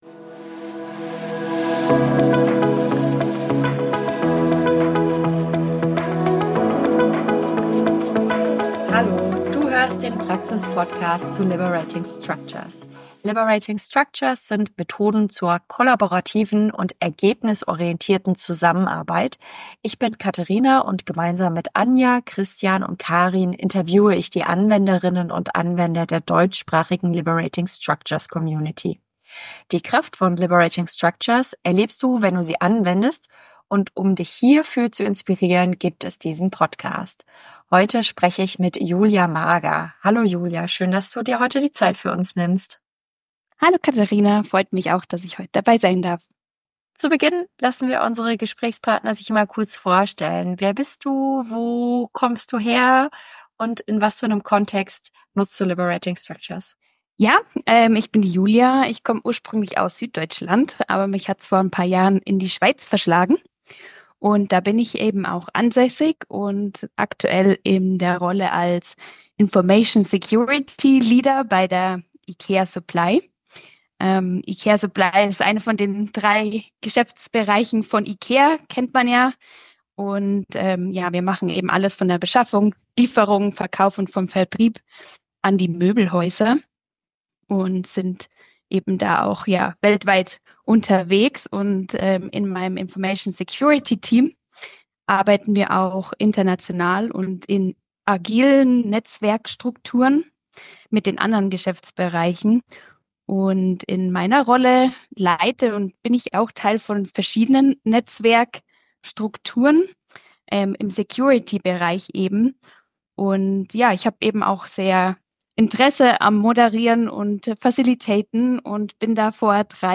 Im Praxispodcast Liberating Structures interviewen wir die Anwenderinnen und Anwender im deutschsprachigen Raum.